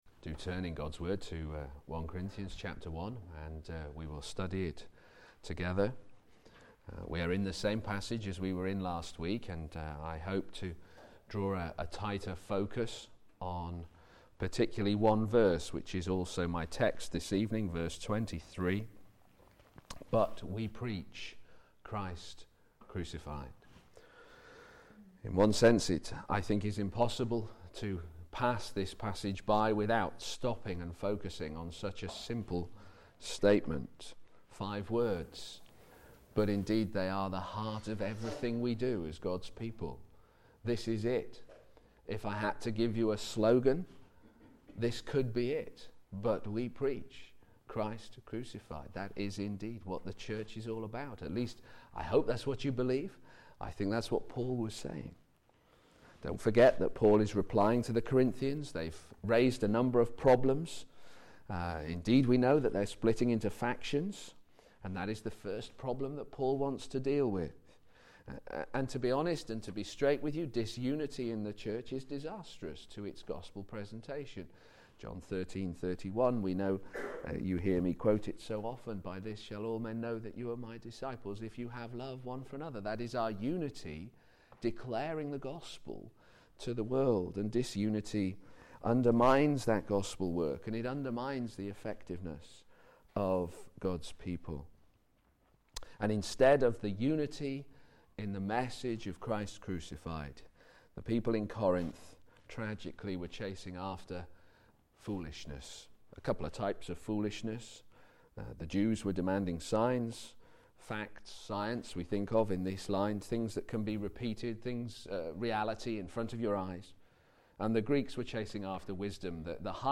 Media Library Media for p.m. Service on Sun 09th Jun 2013 18:30 Speaker
1 Corinthians 1:18-31 Series: Working together to advance the Gospel Theme: We preach Christ crucified. Sermon